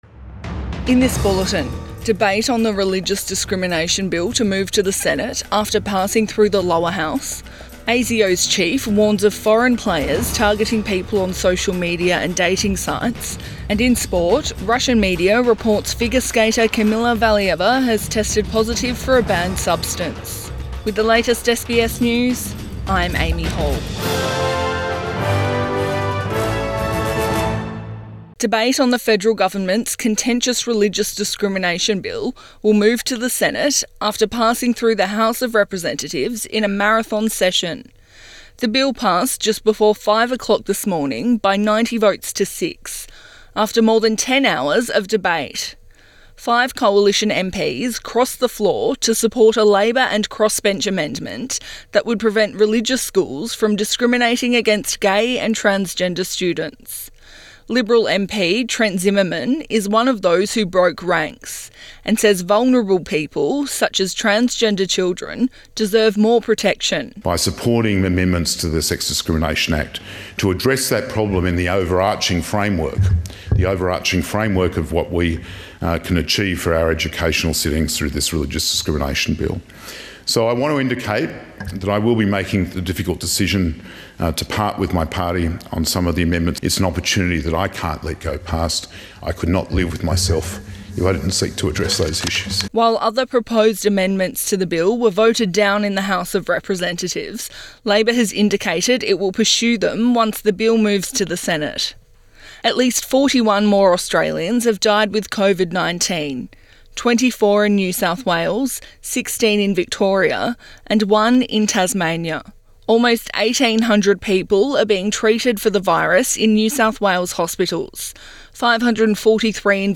Midday bulletin 10 February 2022